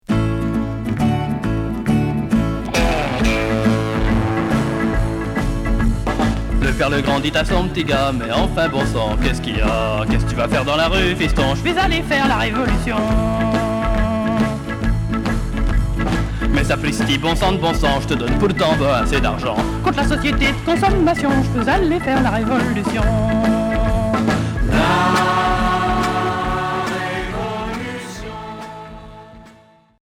Pop Sixième 45t retour à l'accueil